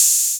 808-OpenHiHats17.wav